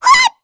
Lavril-Vox_Attack3_kr.wav